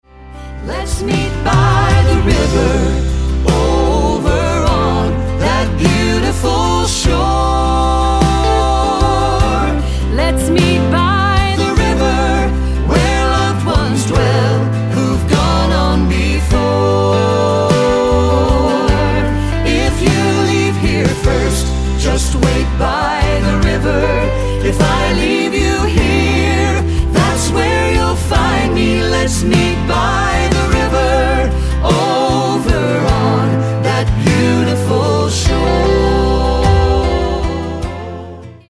studio album
gospel